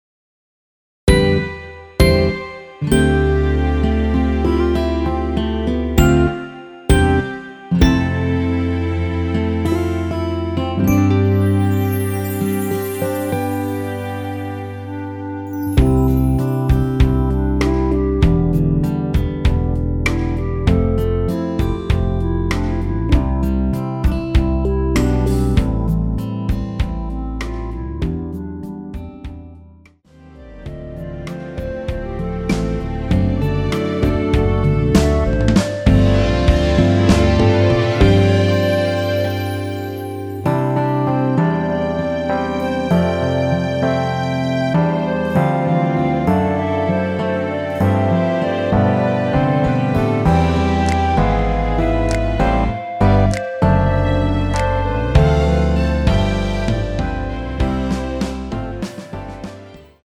원키에서(-2)내린 1절후 후렴으로 진행되는 멜로디 포함된 MR입니다.
앞부분30초, 뒷부분30초씩 편집해서 올려 드리고 있습니다.
중간에 음이 끈어지고 다시 나오는 이유는